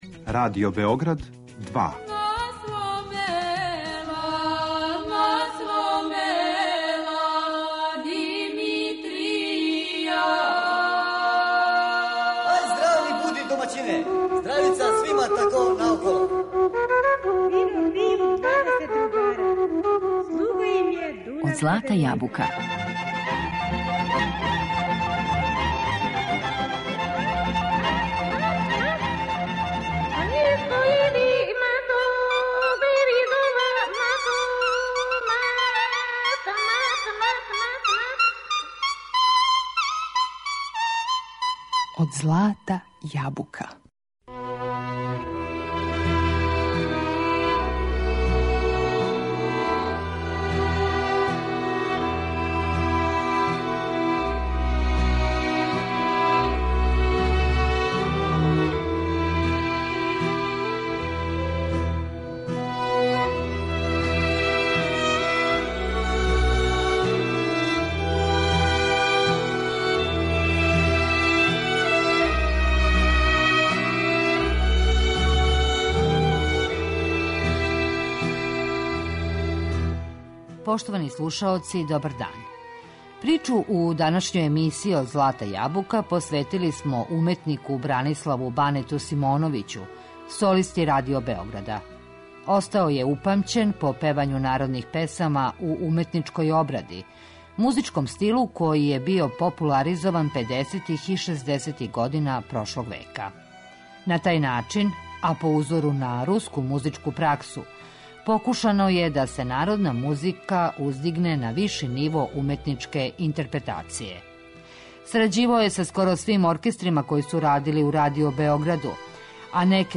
Данашњи музички портрет